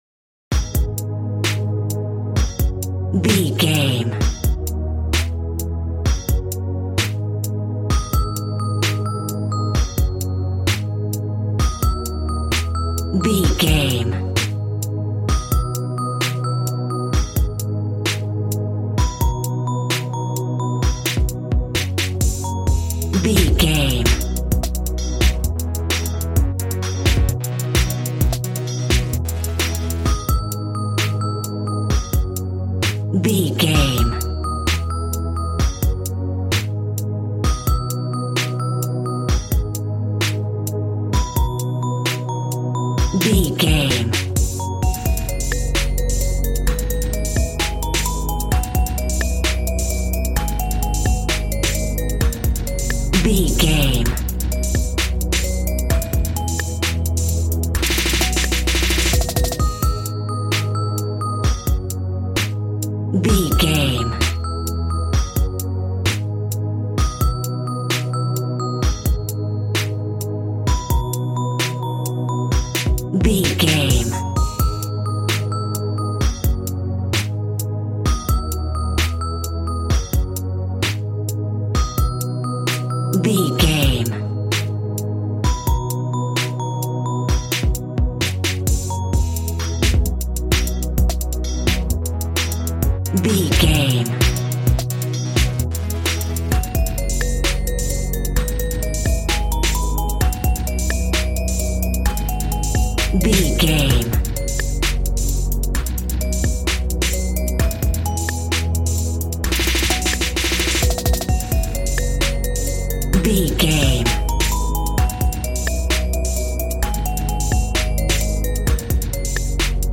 Aeolian/Minor
A♭
calm
smooth
synthesiser
piano